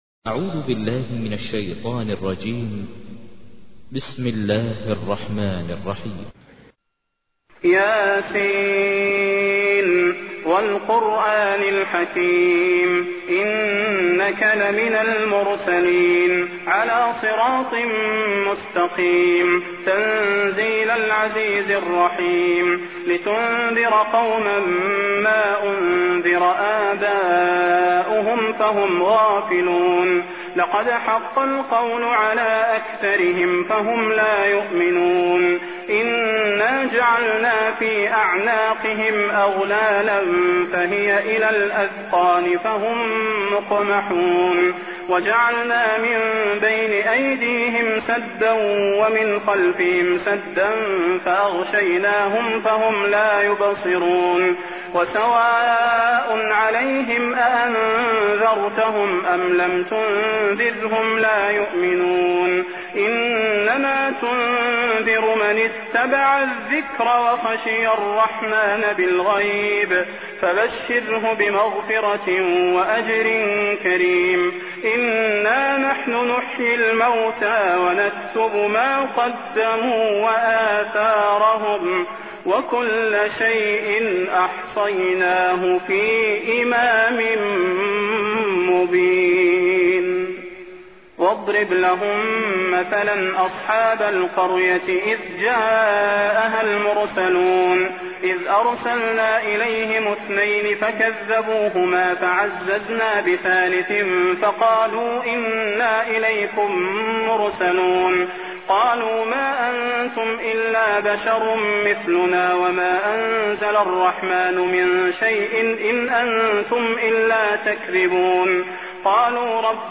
تحميل : 36. سورة يس / القارئ ماهر المعيقلي / القرآن الكريم / موقع يا حسين